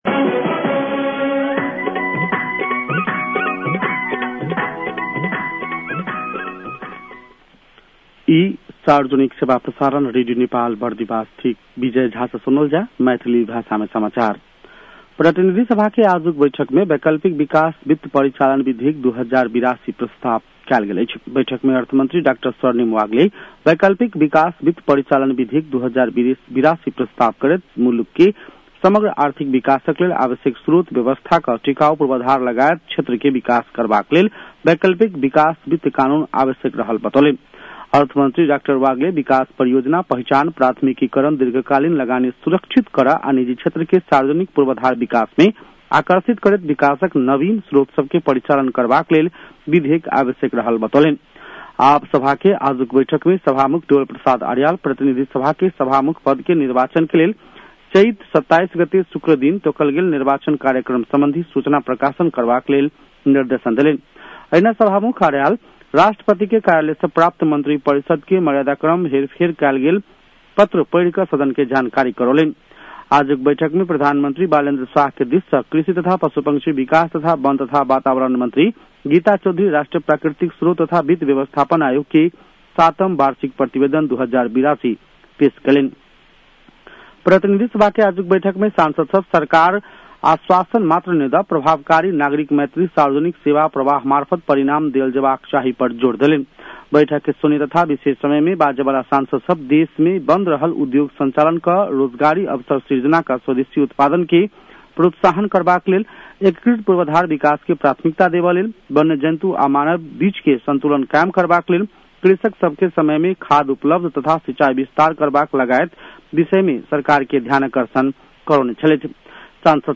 An online outlet of Nepal's national radio broadcaster
मैथिली भाषामा समाचार : २५ चैत , २०८२